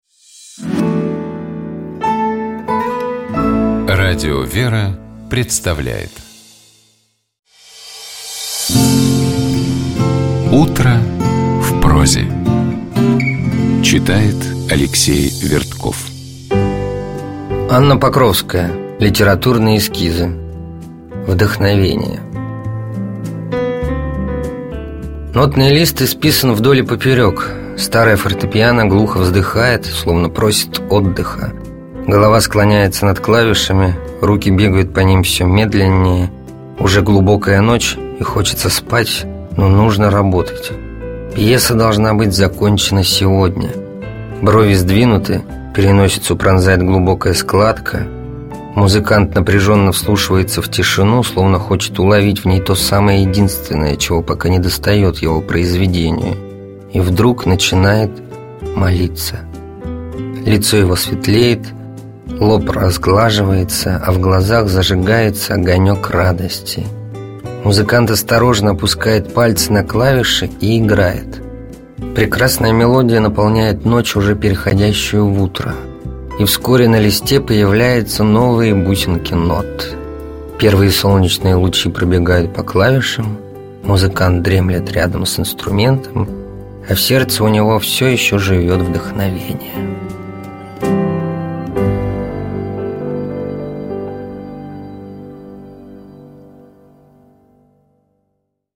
Текст Анны Покровской читает Алексей Вертков.